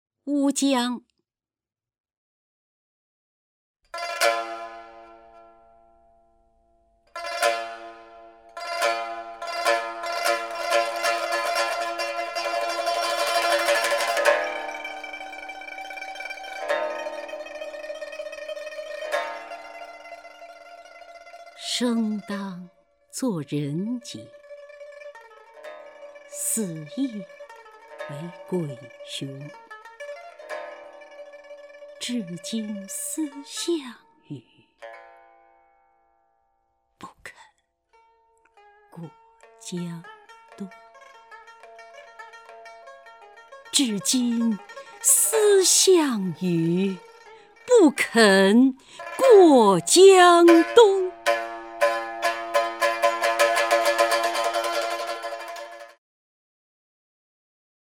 姚锡娟朗诵：《乌江/夏日绝句》(（南宋）李清照)
名家朗诵欣赏 姚锡娟 目录